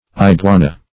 Iguana \I*gua"na\, n. [Sp. iguana, from the native name in